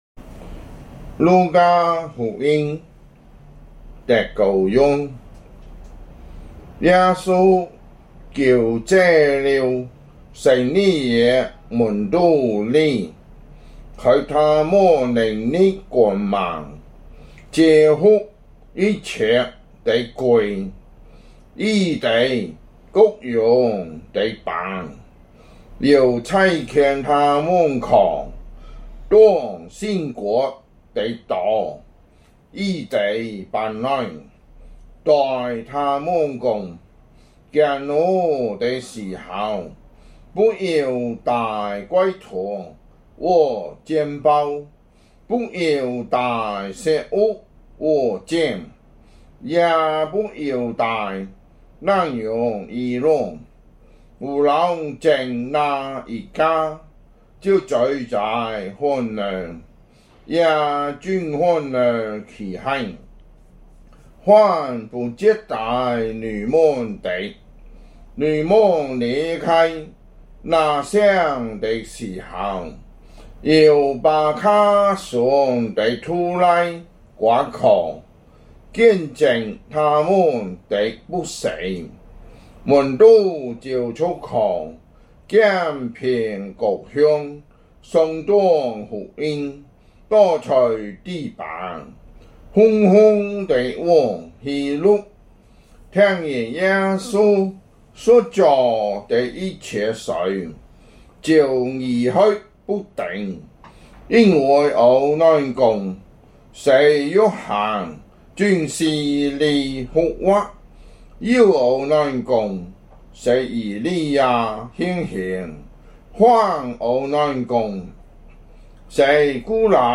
福州話有聲聖經 路加福音 9章